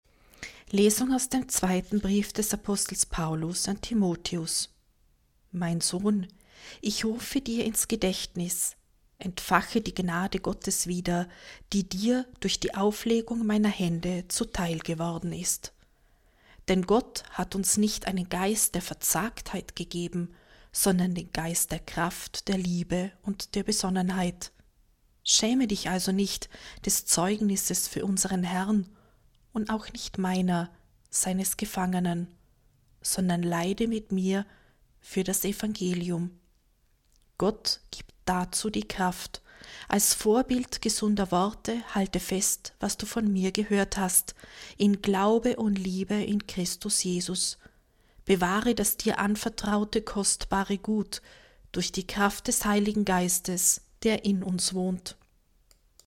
Wenn Sie den Text der 2. Lesung aus dem zweiten Brief des Apostels Paulus